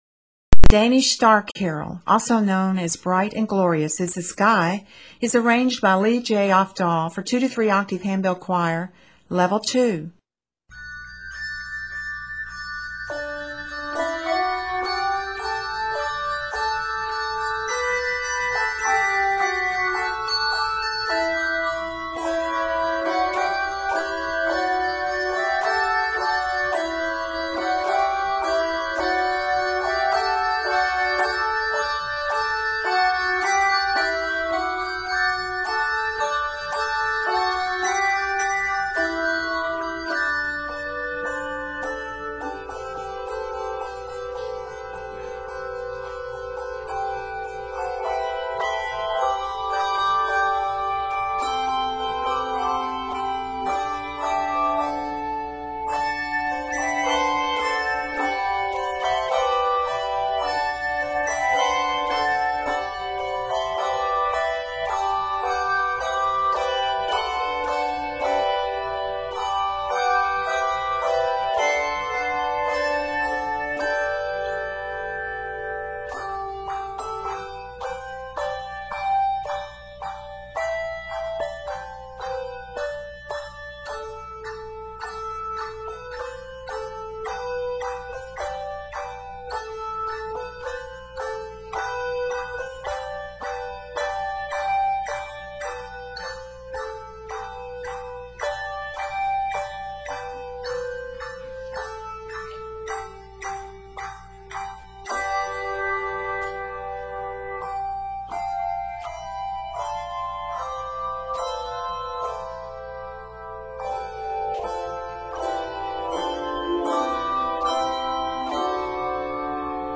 for 2-3 handbell choir